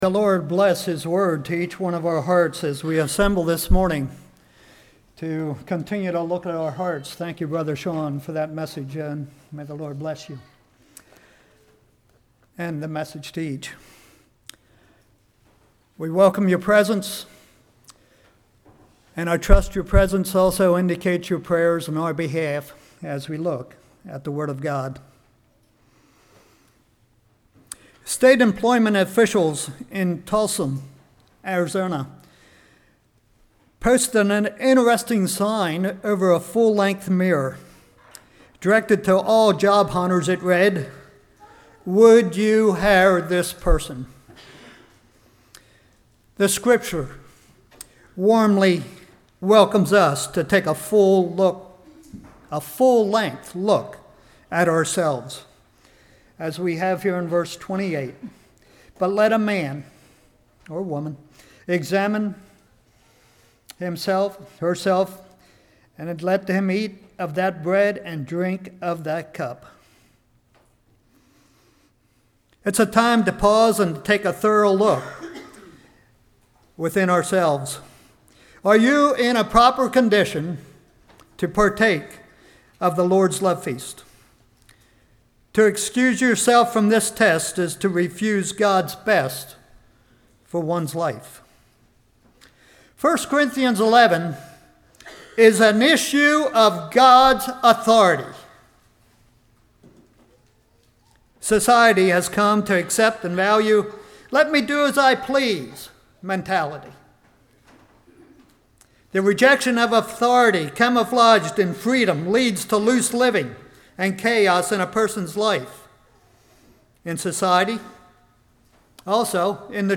1 Corinthians 11:1-34 Service Type: Morning « What Is Your Spiritual Temperature?